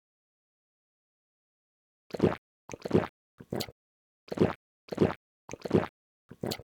drink1.ogg